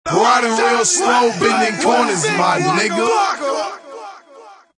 Tm8_Chant43.wav